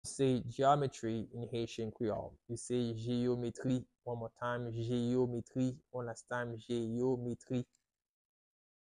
How to say "Geometry" in Haitian Creole - "Jeyometri" pronunciation by a native Haitian Creole tutor
“Jeyometri” Pronunciation in Haitian Creole by a native Haitian can be heard in the audio here or in the video below:
How-to-say-Geometry-in-Haitian-Creole-Jeyometri-pronunciation-by-a-native-Haitian-Creole-tutor.mp3